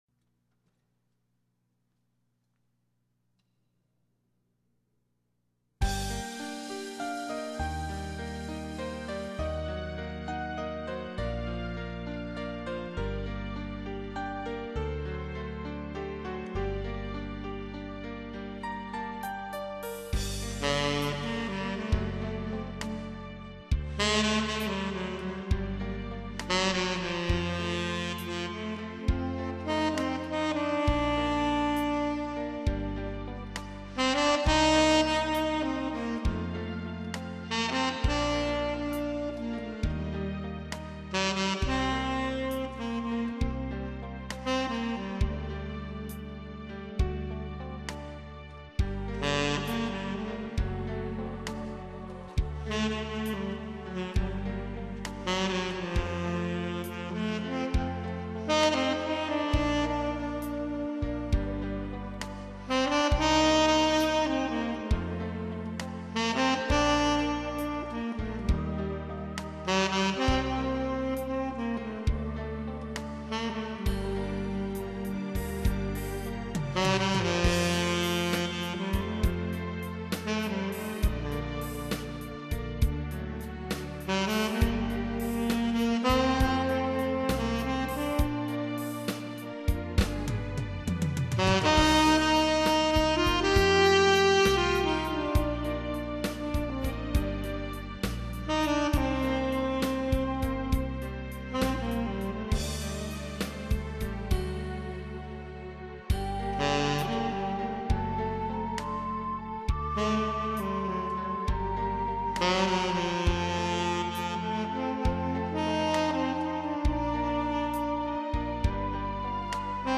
테너색소폰으로 연주했습니다.